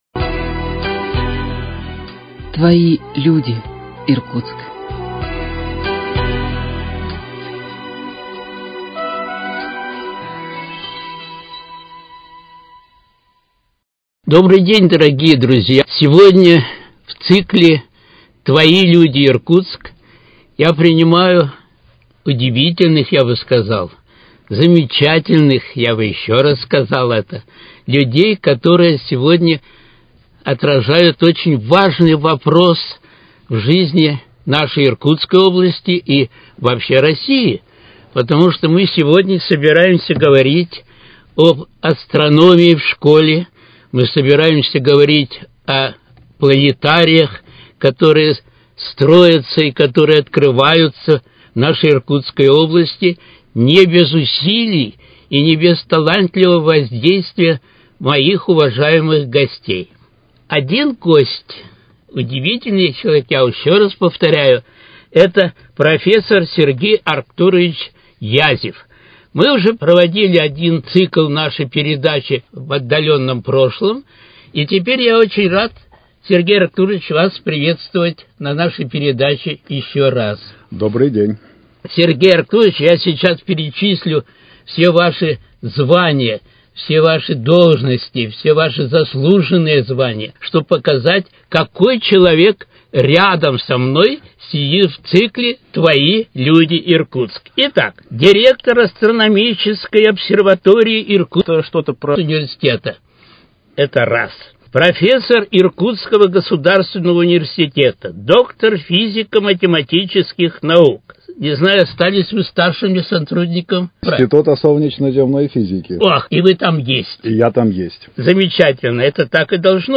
Твои люди, Иркутск: Беседа